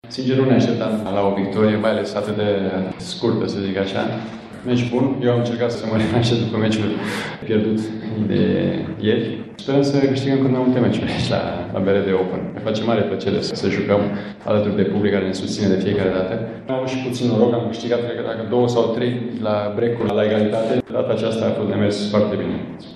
de la conferinţa de presă de după meci